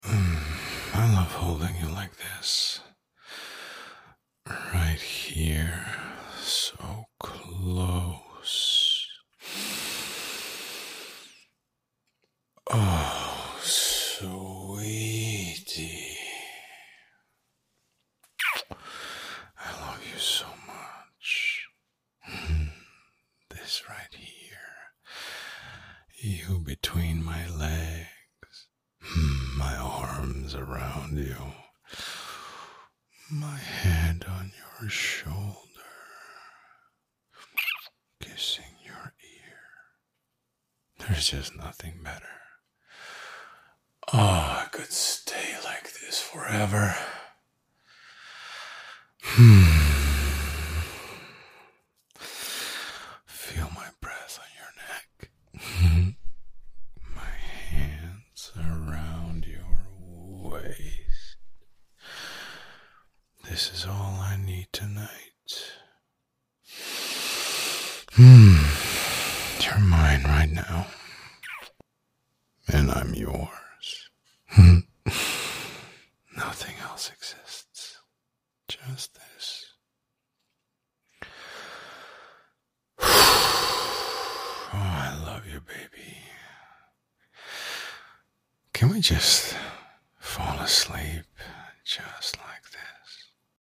Full 8D left / right sound effects free download
A soft spoken man. A voice that holds you. You wanted more of that safe, protective whisper in your ear — so here it is.